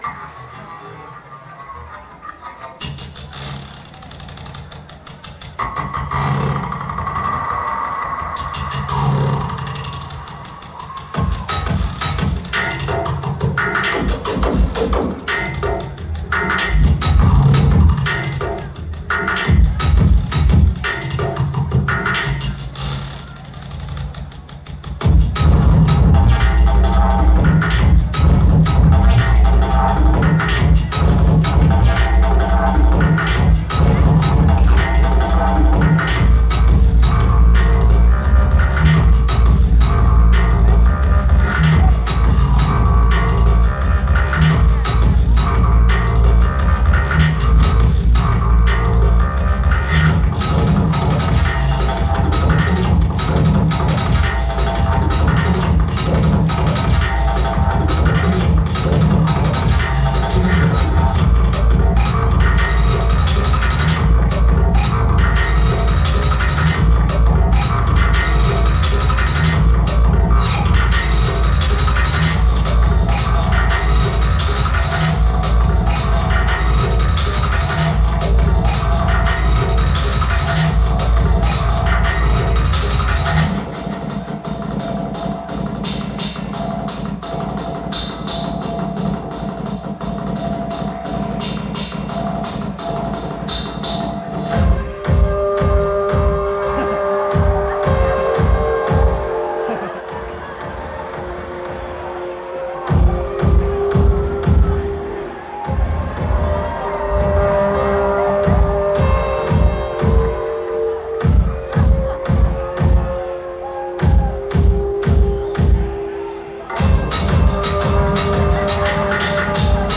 venue Avalon Ballroom